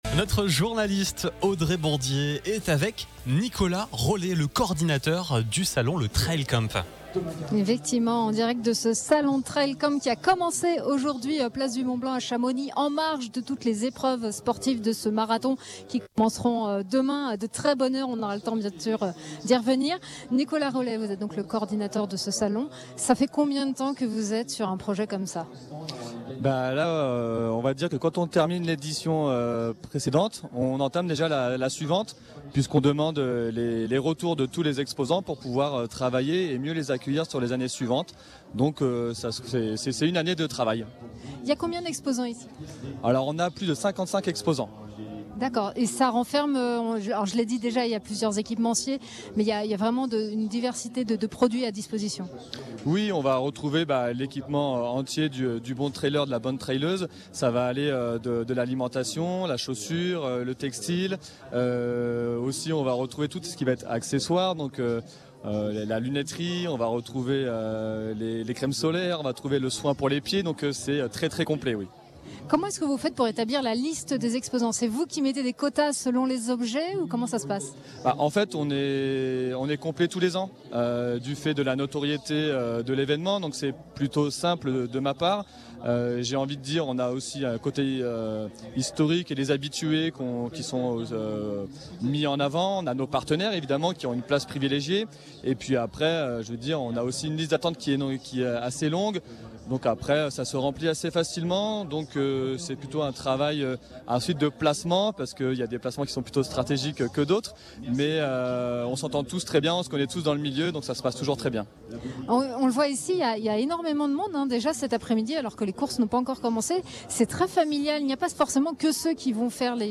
Duplex depuis le village exposants Place du Mont-Blanc
Ce jeudi après-midi, nous étions en direct en duplex depuis le village des exposants, pour une émission spéciale consacrée à cet événement sportif.